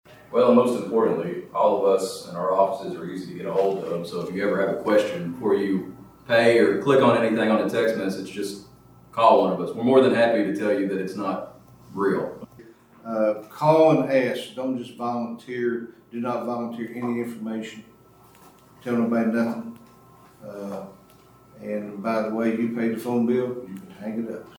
Judge-Executive Kota Young and Sheriff Weedman both stressed the importance of calling to verify before clicking on any links or responding to suspicious text messages.